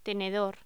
Locución: Tenedor
voz